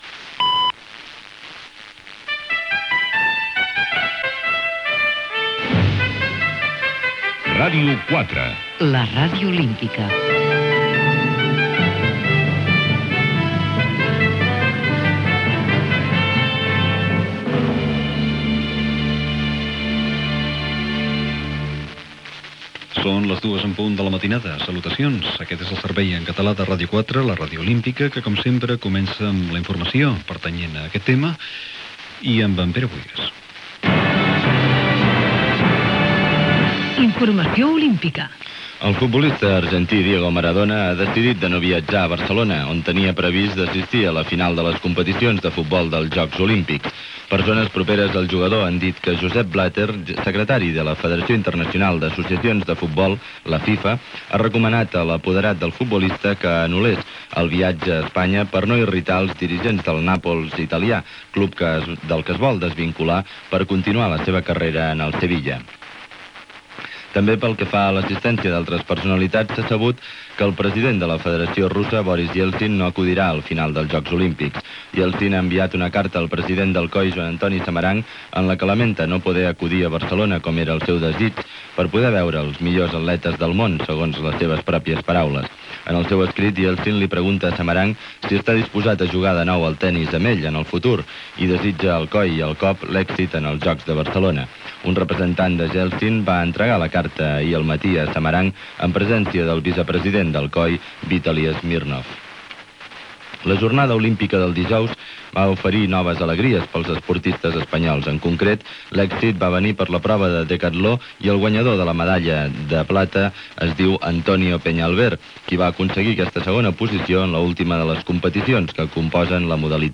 Hora, tema musical, notícies internacionals, hora, tema musical, actuació a la Vila Olímpica, sintonia de la ràdio Gènere radiofònic Informatiu